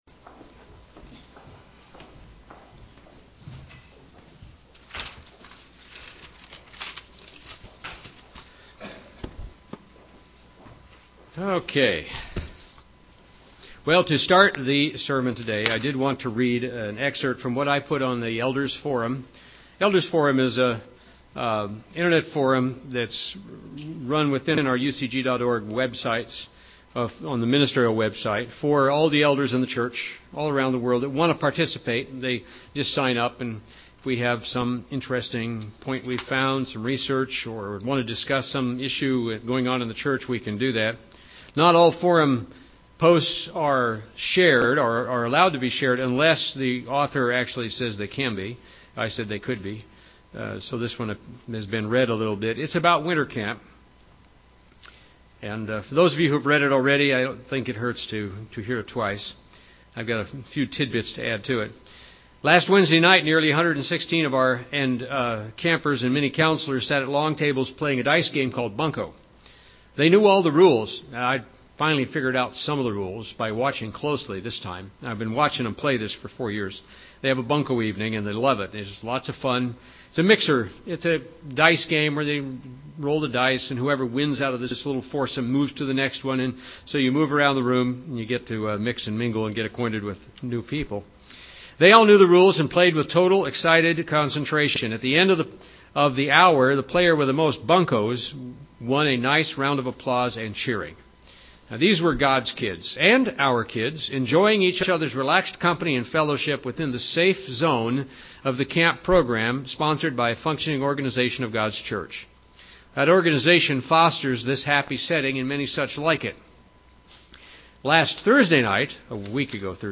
Parable of the Sower and the Zone UCG Sermon Studying the bible?